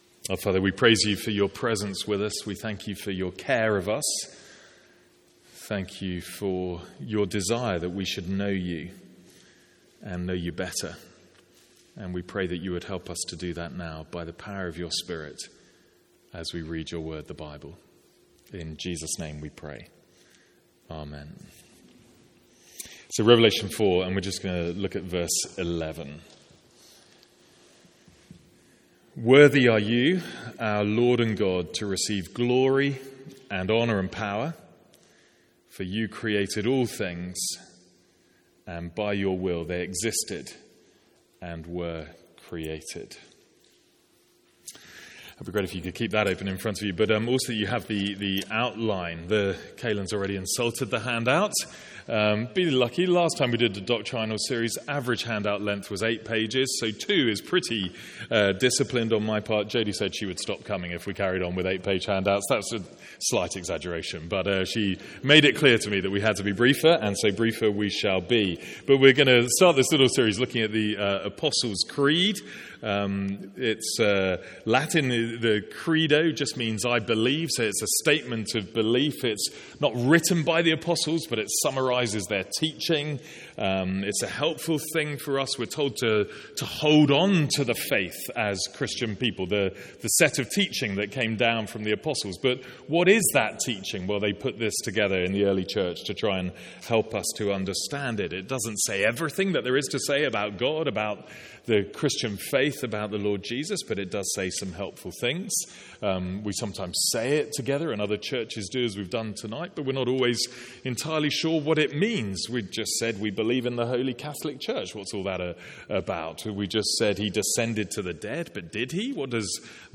Sermons | St Andrews Free Church
From our evening series on the Apostles Creed.